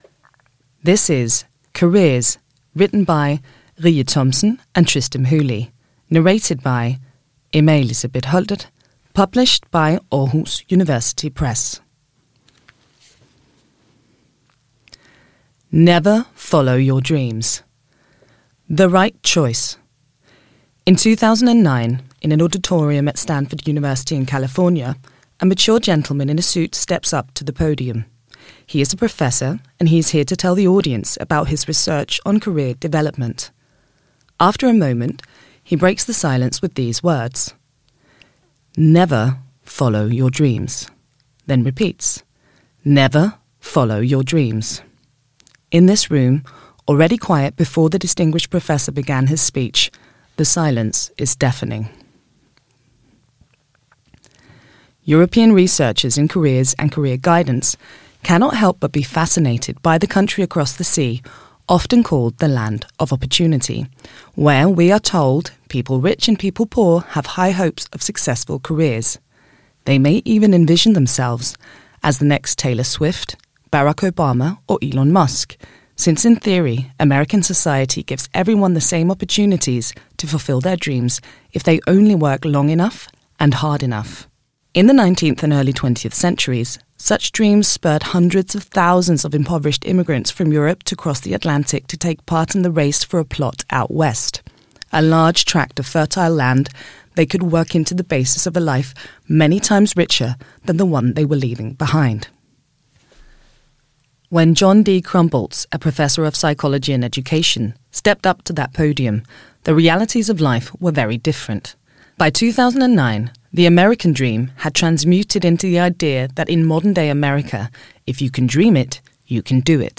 careers_lydbog.mp3